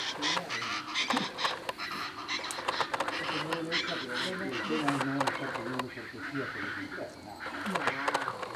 Aracuã-do-pantanal (Ortalis canicollis)
Nome em Inglês: Chaco Chachalaca
Fase da vida: Adulto
Localidade ou área protegida: Refugio de Vida Silvestre El Cachapé
Condição: Selvagem
Certeza: Gravado Vocal
charata.mp3